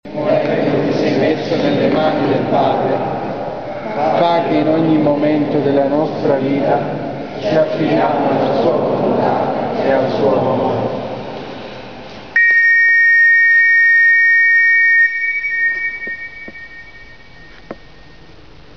Fischio lungo (clicca)
(esprime il silenzio che cala sul campo prima della notte. È il silenzio dell'uomo della croce che non ha più parole da dire ma solo sangue da offrire)
a 043FISCHIO11.mp3